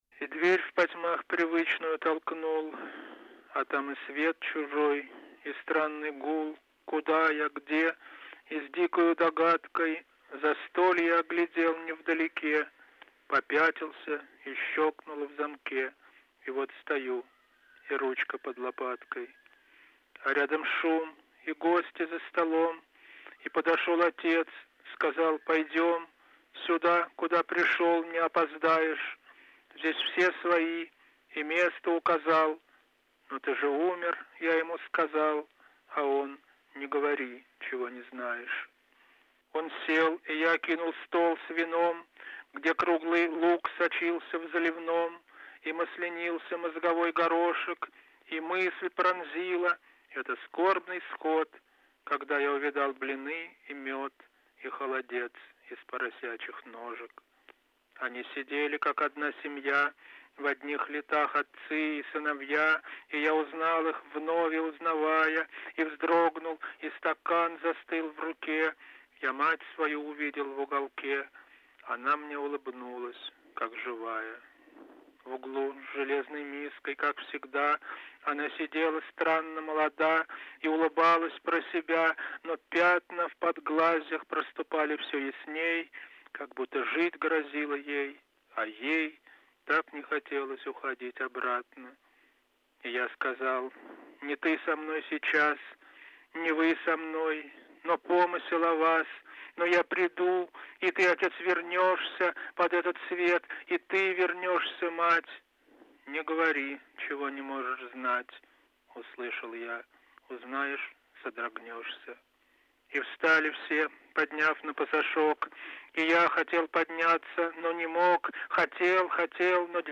Олег Чухонцев читает свои стихи